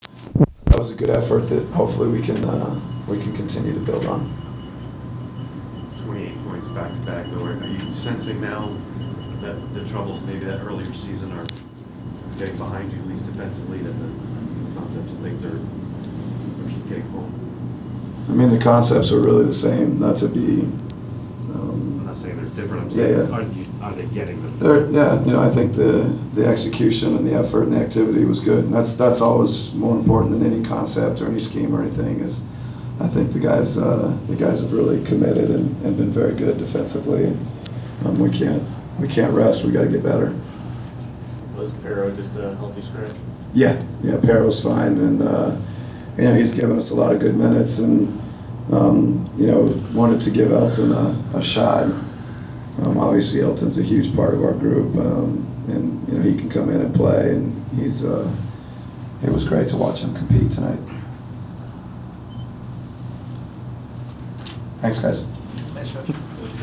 Inside the Inquirer: Post-game interview with Atlanta Hawks’ coach Mike Budenholzer (11/29/14)
We attended the post-game press conference of Atlanta Hawks’ coach Mike Budenholzer following the team’s 105-75 home win over the Charlotte Hornets on Nov. 29.